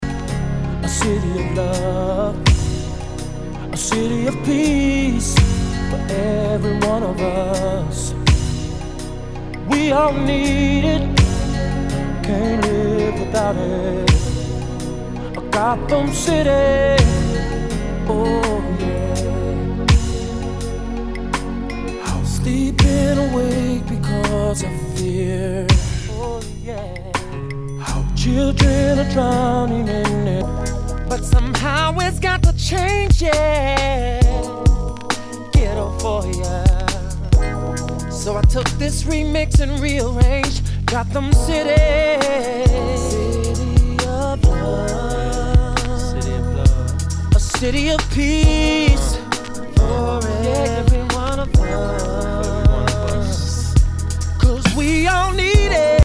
HIPHOP/R&B
全体にチリノイズが入ります